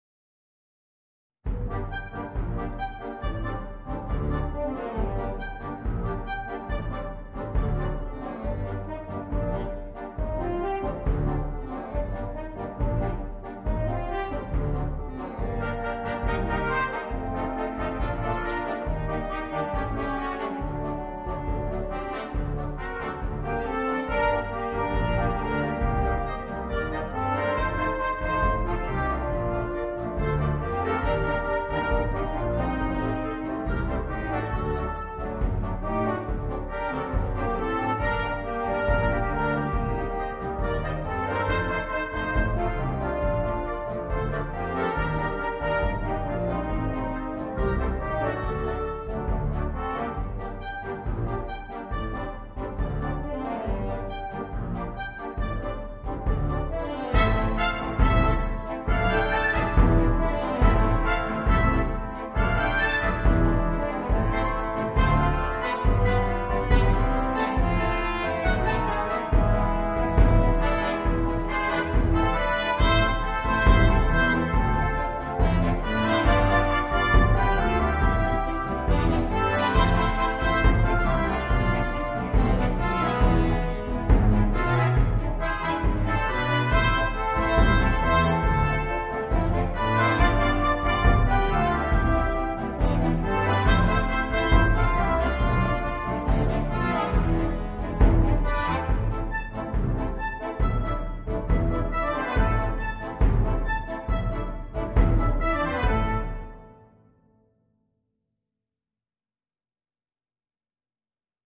для духового оркестра.